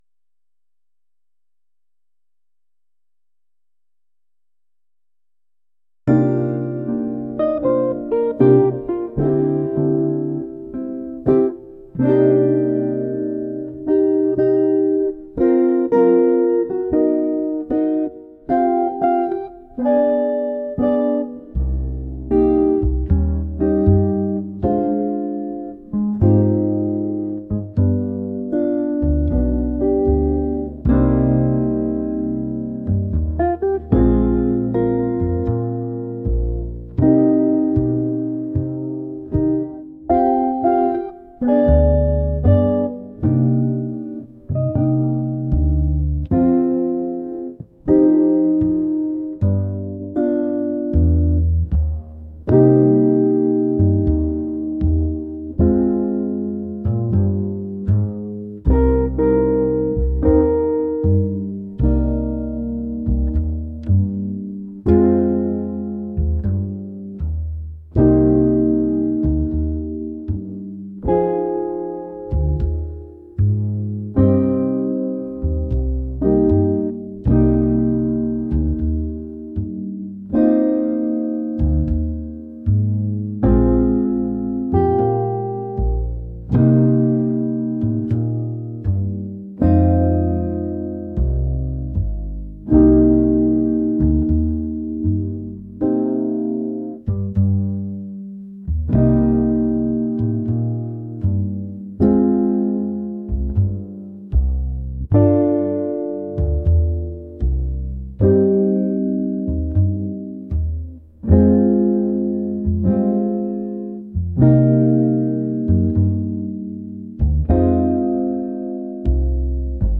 mellow | smooth | jazz